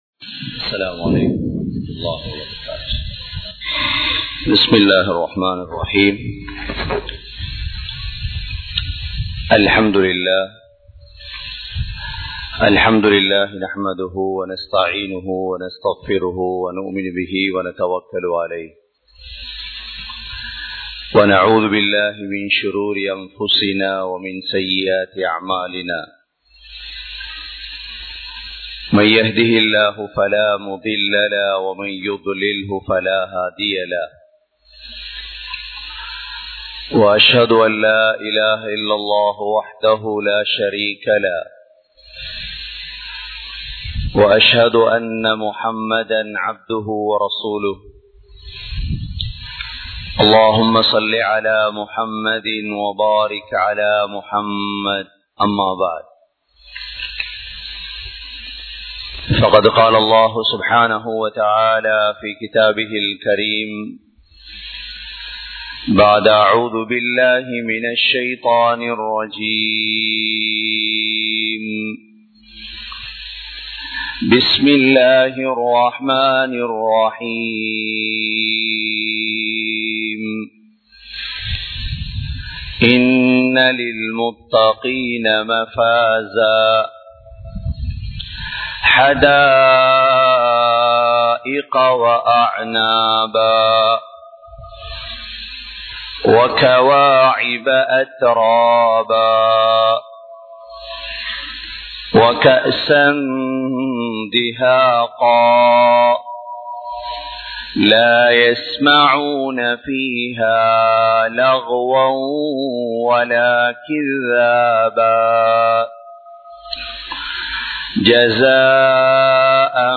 Marumaiel Allahvin Neethi (மறுமையில் அல்லாஹ்வின் நீதி) | Audio Bayans | All Ceylon Muslim Youth Community | Addalaichenai
Dehiwela, Muhideen (Markaz) Jumua Masjith